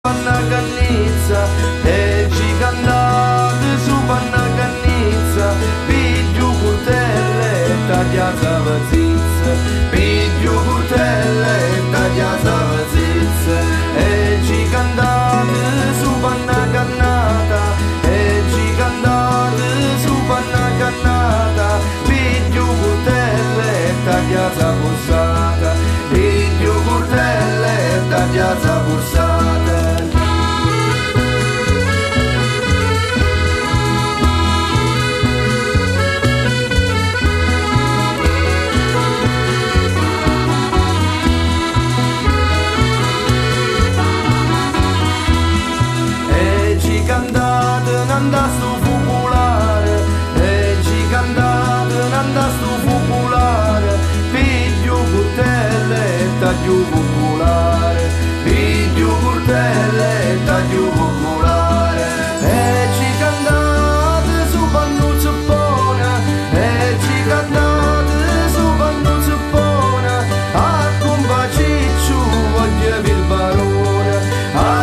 Voce e Chitarra
Fisarmonica
Percussioni